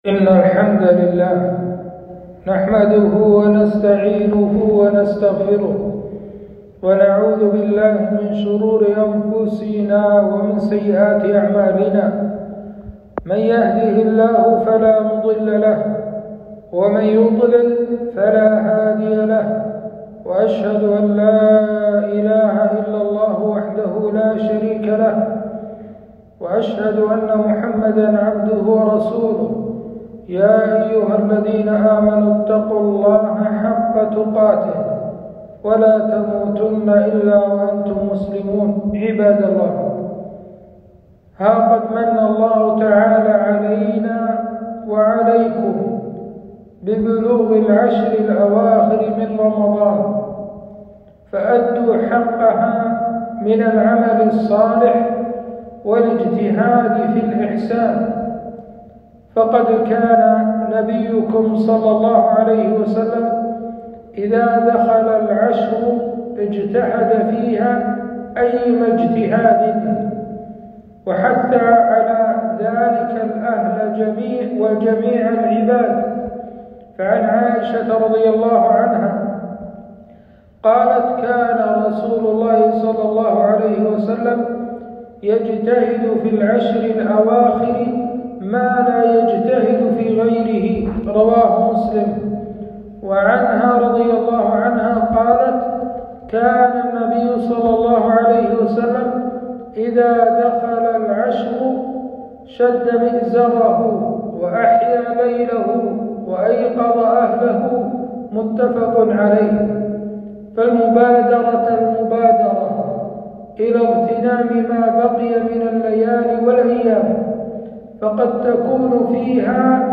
خطبة - ليلة القدر خير من ألف شهر